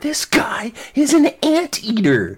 anteater.mp3